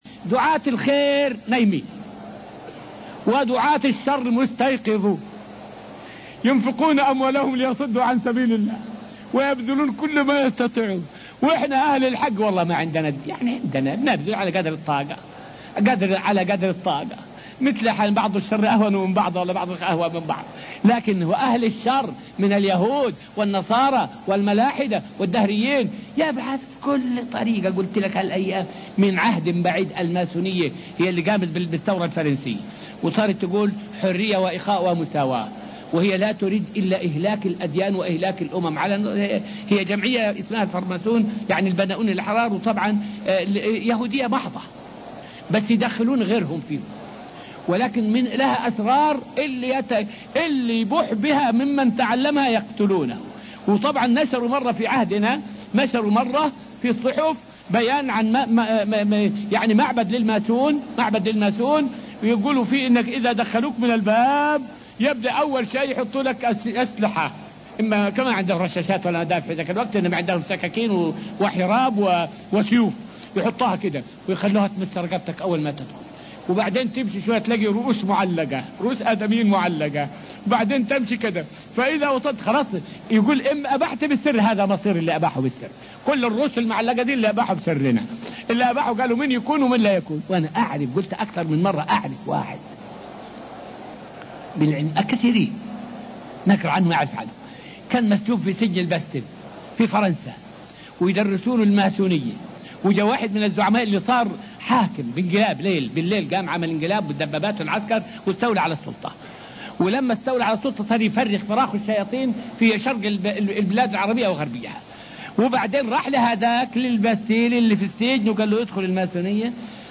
فائدة من الدرس الرابع والعشرون من دروس تفسير سورة البقرة والتي ألقيت في المسجد النبوي الشريف حول بقاء دين الله بالرغم من كيد الكائدين.